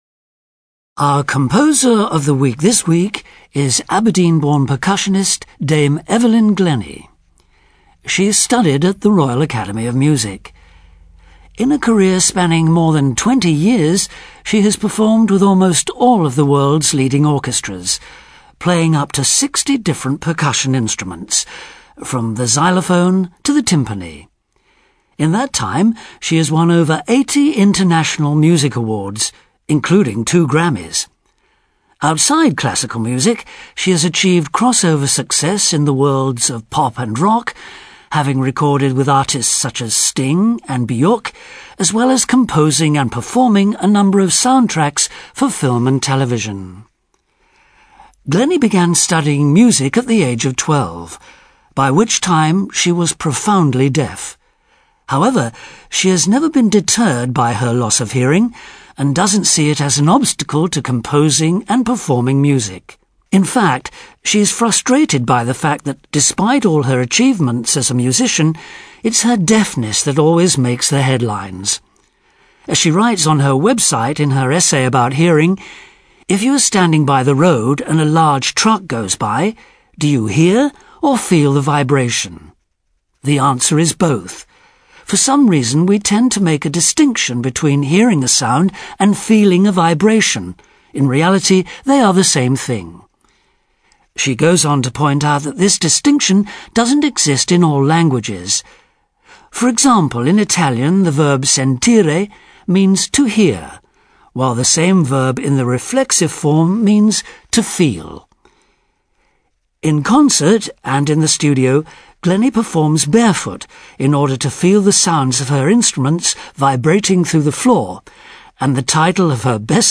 ACTIVITY 128: You are going to listen to someone talking about the percussionist Evelyn Glennie.